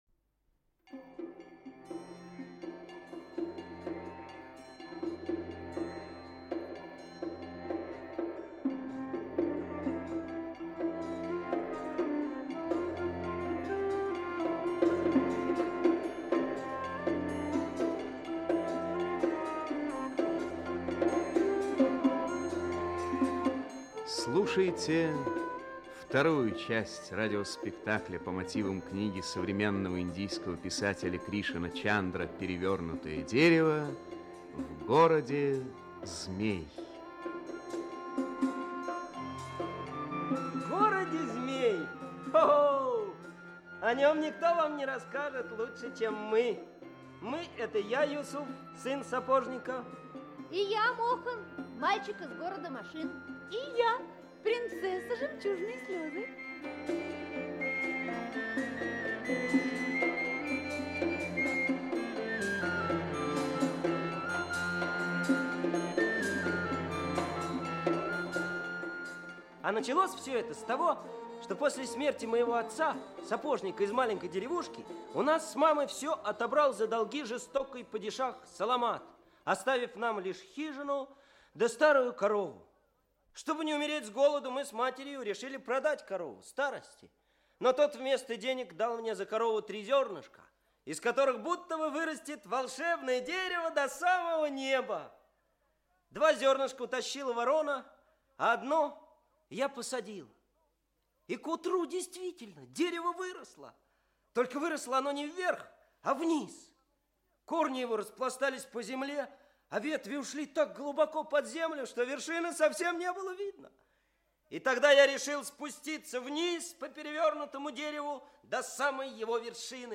Аудиокнига Перевернутое дерево. Часть 2. «В городе змей» | Библиотека аудиокниг
«В городе змей» Автор Кришан Чандар Читает аудиокнигу Алексей Грибов.